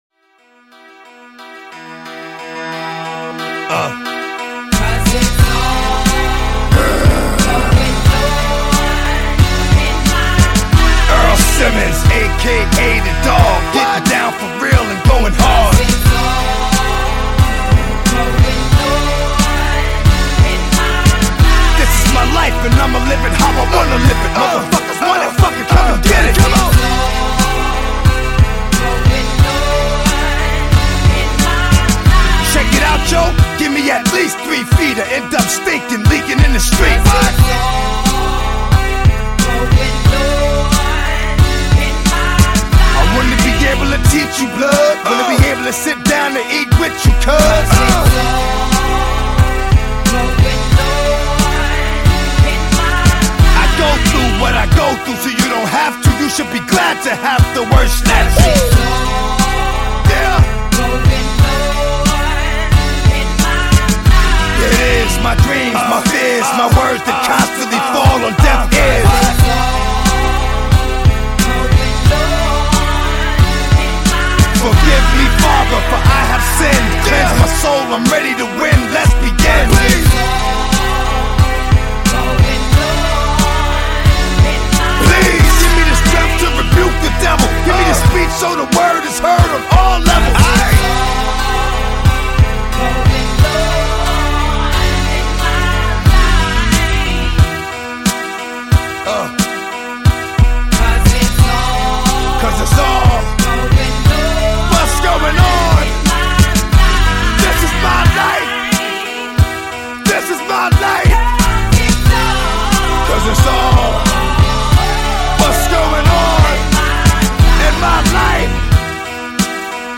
Жанр: Pop
Рэп Хип-хоп.